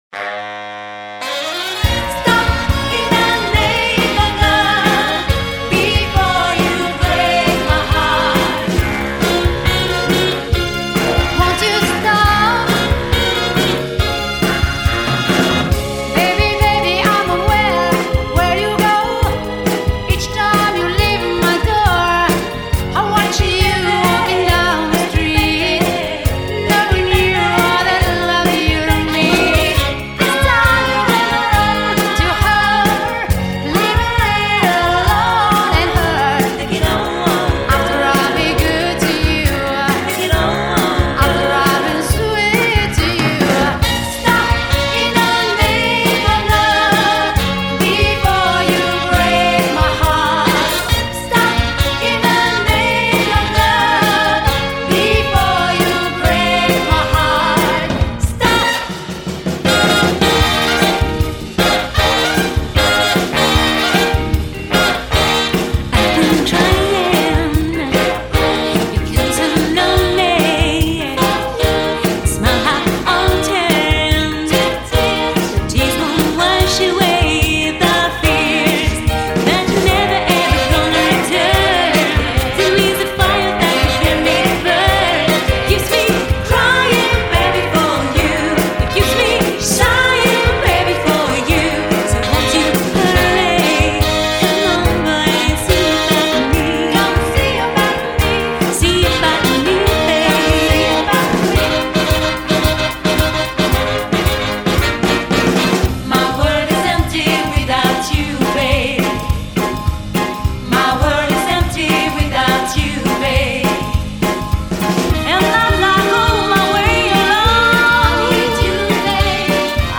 Jazz Soul Funk Disco Divas
Enregistrement live :